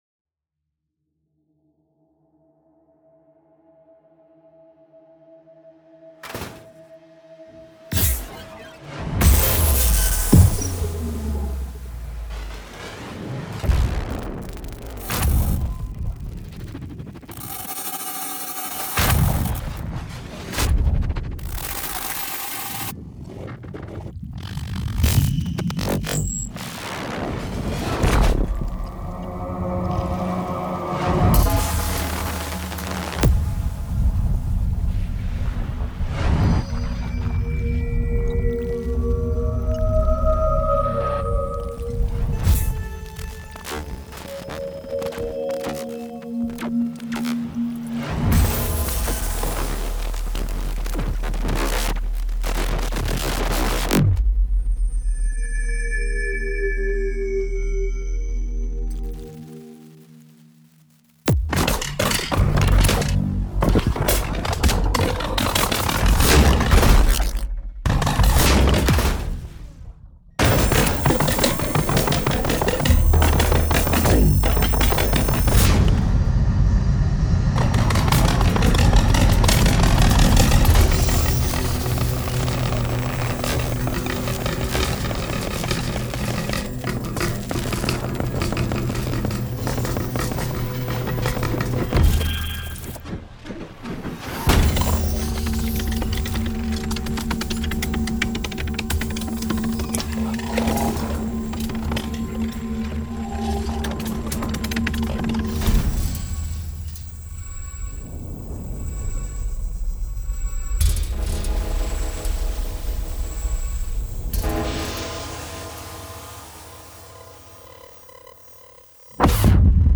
Dévorer l’espace (mp3 version), 2015. Rough stereo mix from octophonic composition (8.1)
This piece received a nomination at the Destellos Foundation’s 8th international electroacoustic composition competition, 2015.